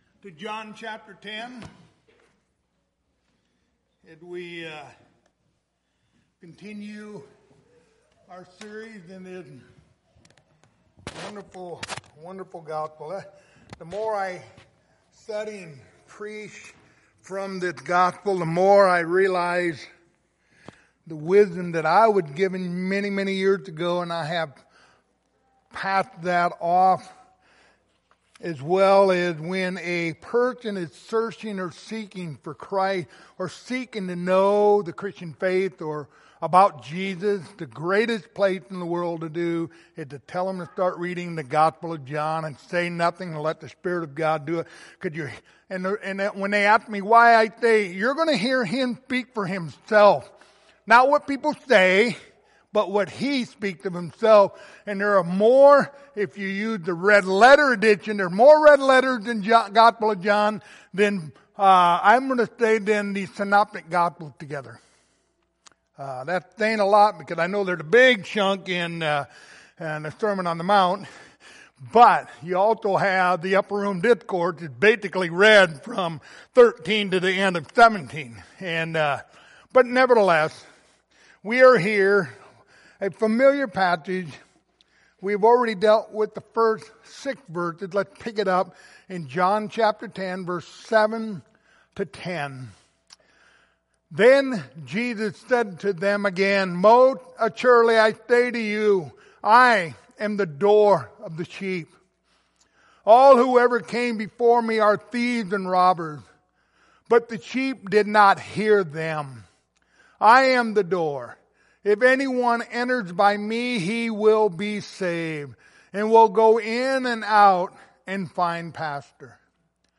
Passage: John 10:7-10 Service Type: Wednesday Evening